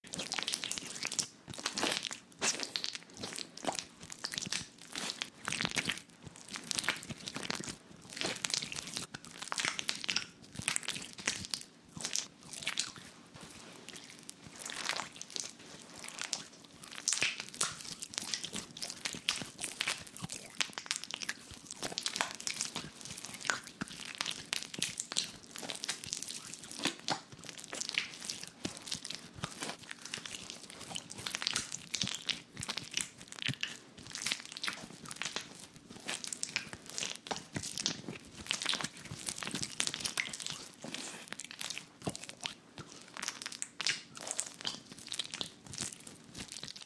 Шуршание геля между пальцами